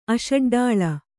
♪ aṣaḍḍāḷa